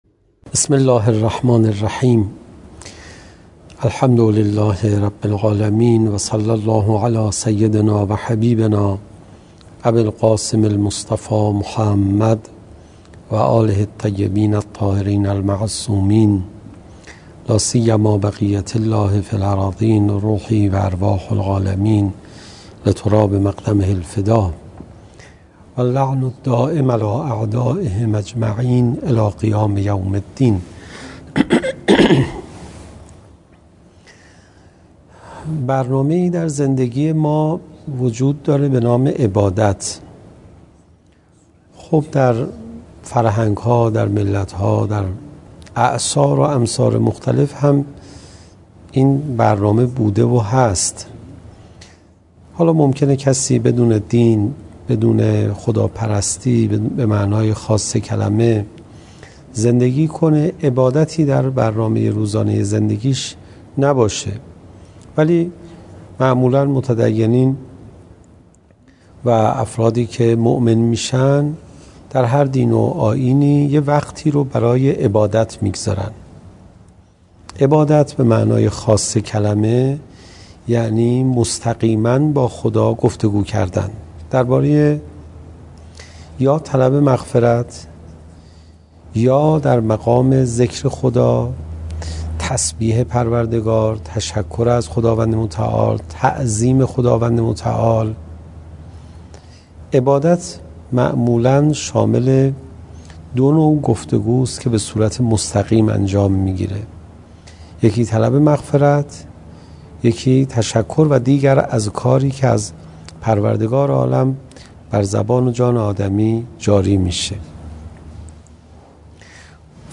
سخنرانی: استفاده از ظرفیت ماه مبارک رمضان در تقویت ...